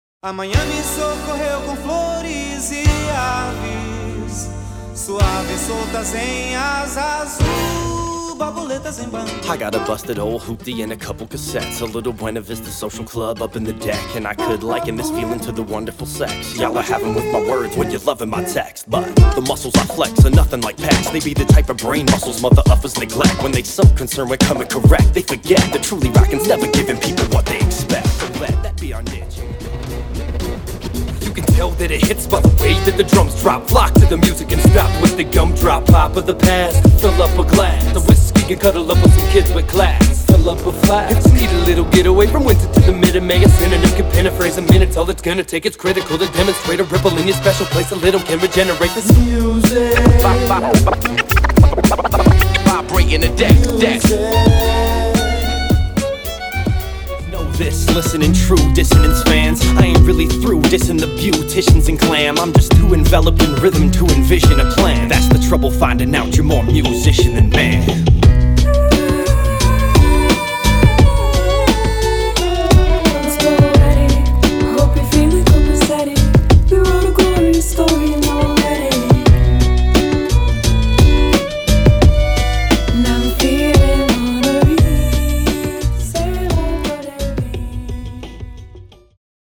Genre/Style: Hip-hop